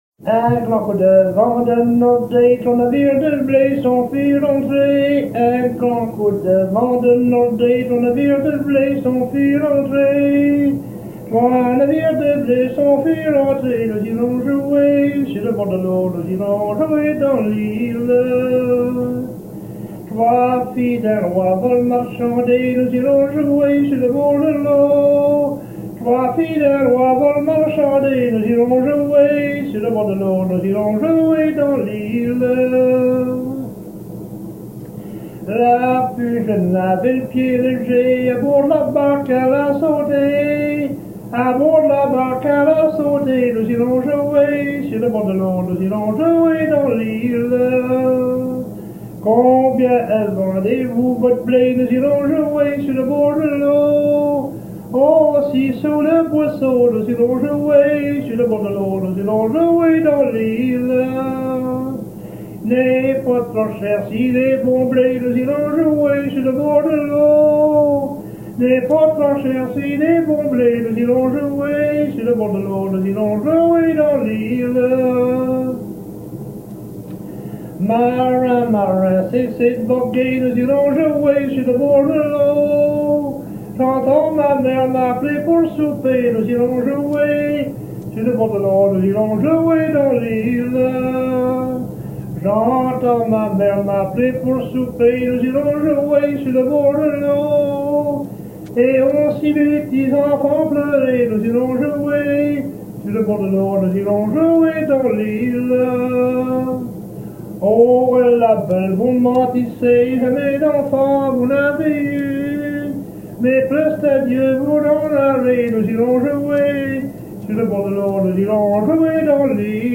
enregistré à Cap-Saint-Georges, île de Terre-Neuve le 16 octobre 1972
Genre laisse
Chansons maritimes